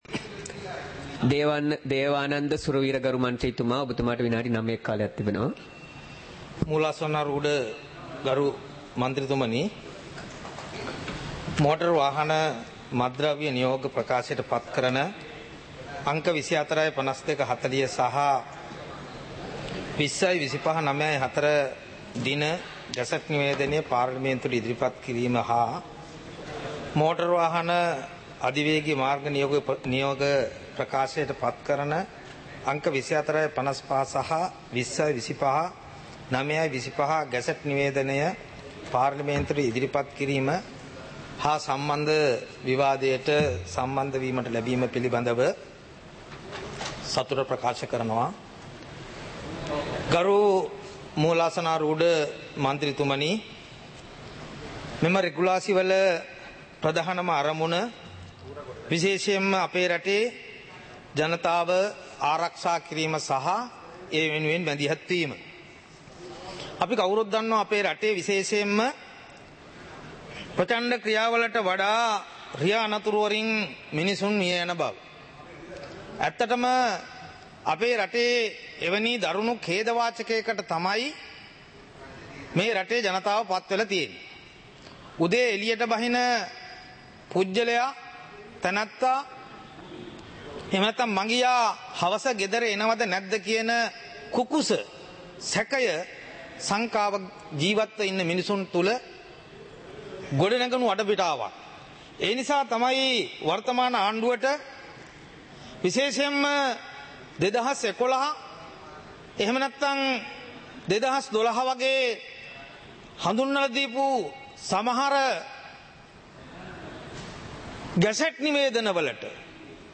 பாராளுமன்ற நடப்பு - பதிவுருத்தப்பட்ட